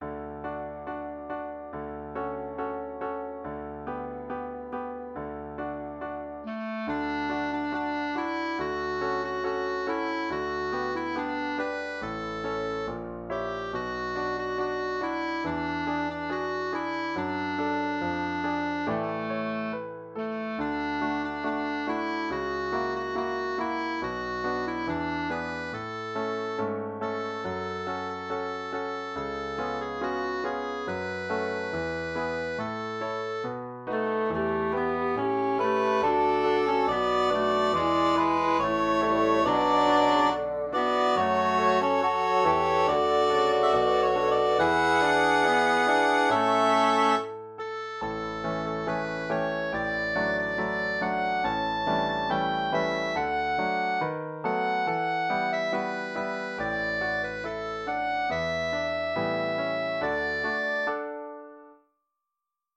037.b-Ég gekk í björg (solo-kór-píanó)-3